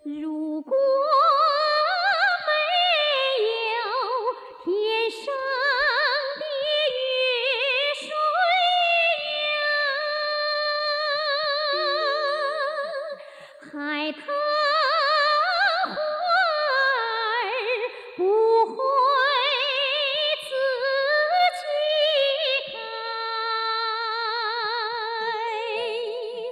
pitchbender.wav